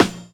Shady_Snare_2.wav